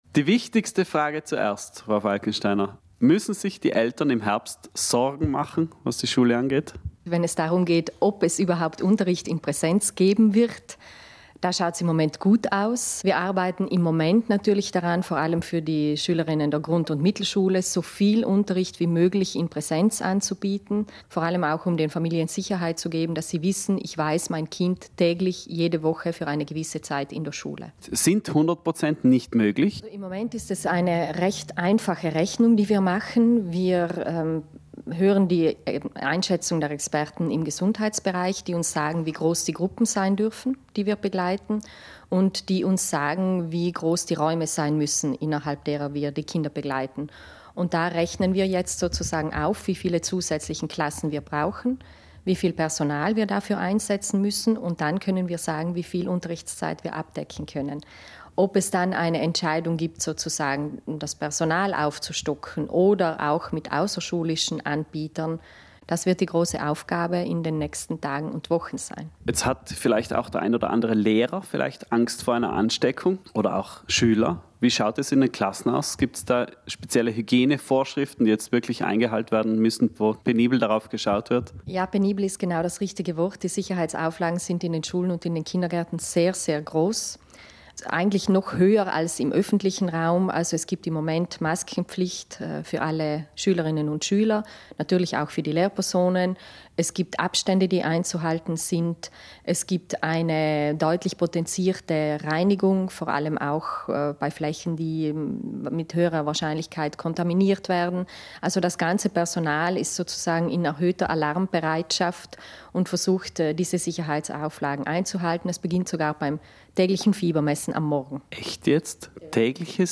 Im ausführlichen Interview erklärt Landesschuldirektorin Sigrun Falkensteiner, wie die Schule im Herbst aus heutiger sicht weitergehen könnte.
Schule_im_Herbst_Aktueller_Stand_ganzes_Interview.MP3